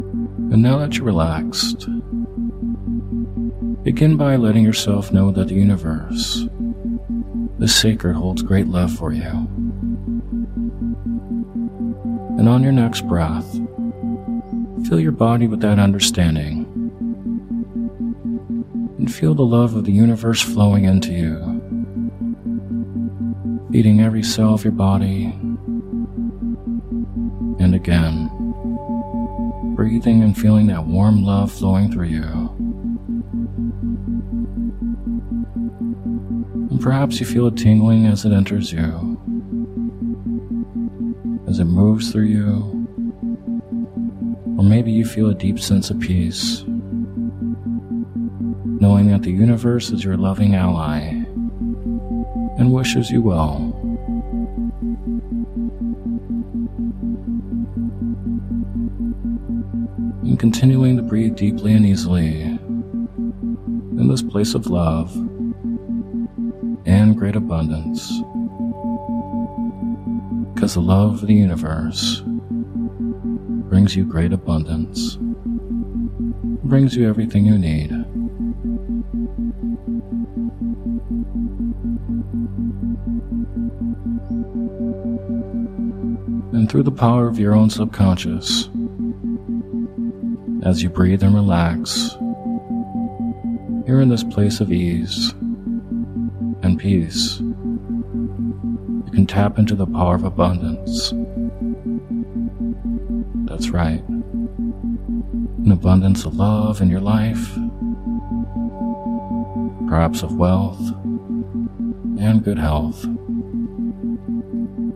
Sleep Hypnosis For Manifesting Abundance In All Areas Of Your Life (The Two Wells) With Isochronic Tones